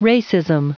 Prononciation du mot racism en anglais (fichier audio)
Prononciation du mot : racism